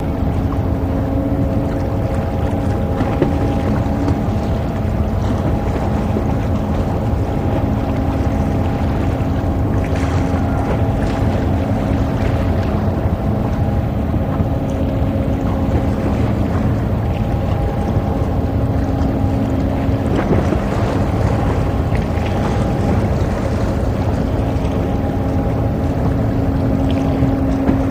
Boat Engine
Harbor Ambience Water Lap, Boat Engine Constant